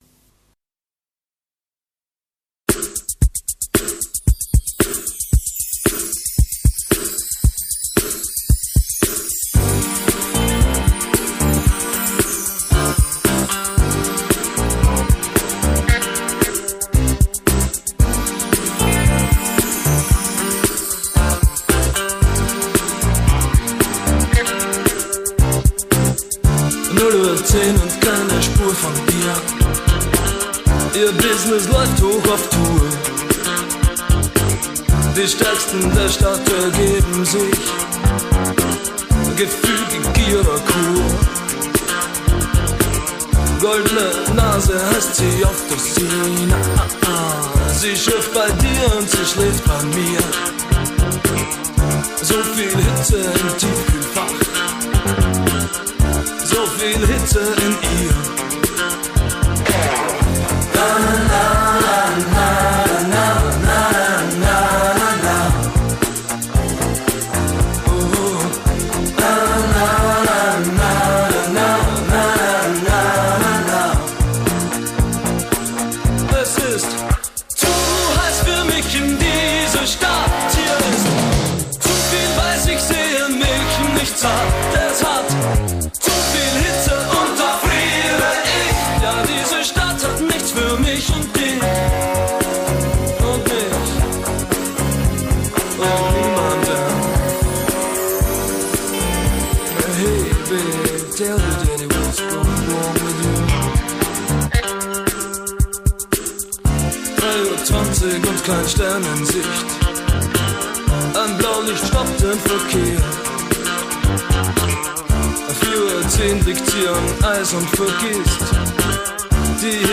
آلبوم نیو ویو و سینث‌پاپ کلاسیک اتریشی
New Wave / Synthpop